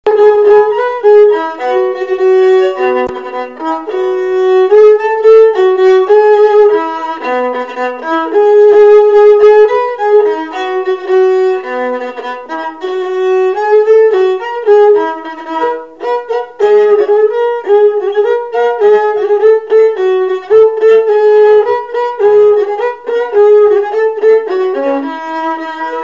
Avant-deux
avant-deux utilisé pour la marche lors des noces
Enquête Arexcpo en Vendée
Pièce musicale inédite